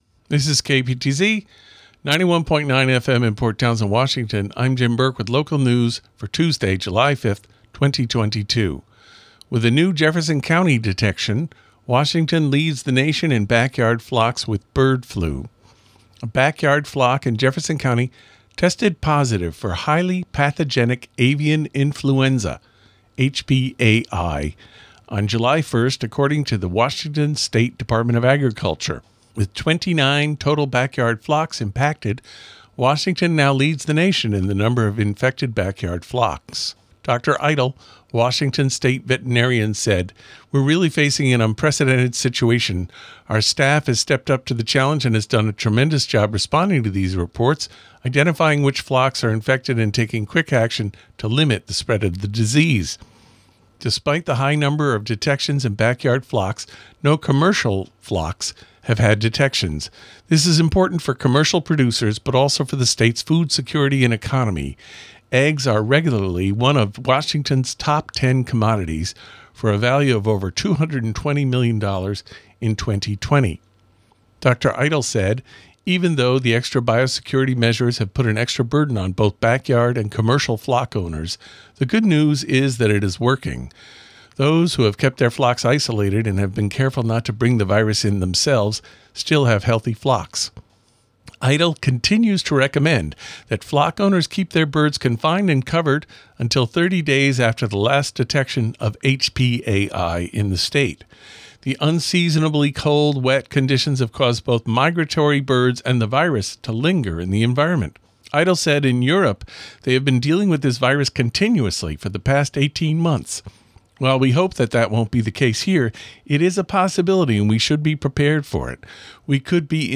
220705 Local News Tues